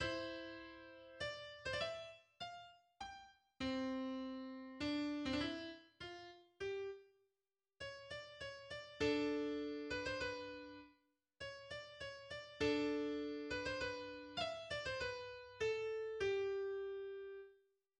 Symphonie «no 46» en do majeur
Genre Symphonie
Première reprise du Menuetto :